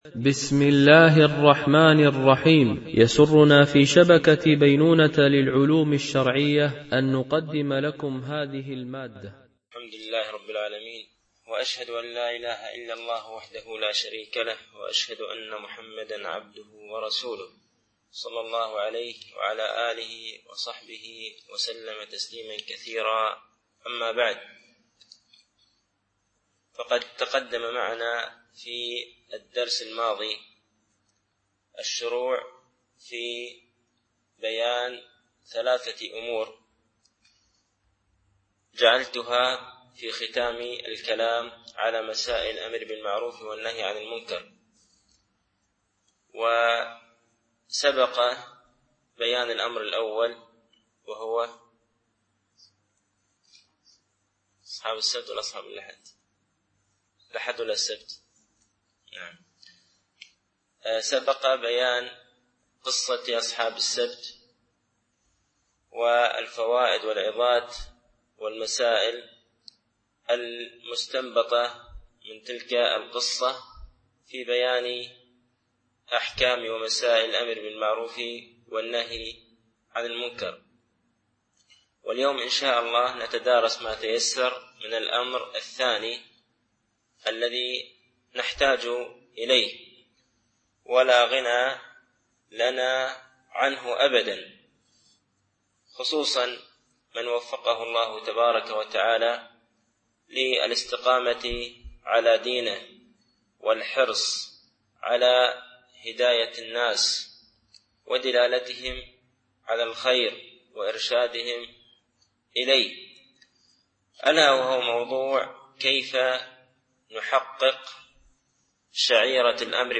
الألبوم: شبكة بينونة للعلوم الشرعية التتبع: 168 المدة: 59:49 دقائق (13.73 م.بايت) التنسيق: MP3 Mono 22kHz 32Kbps (CBR)